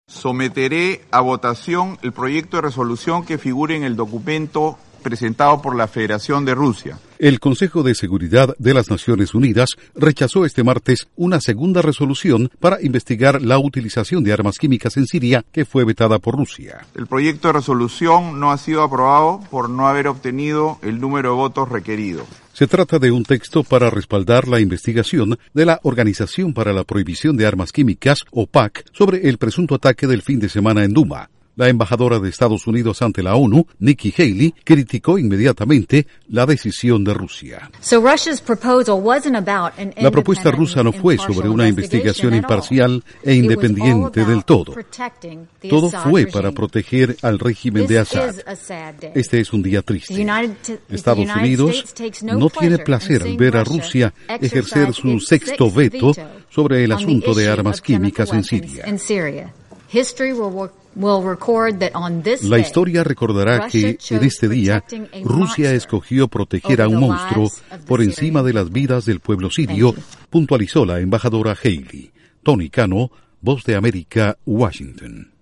Con audios de la embajadora Haley.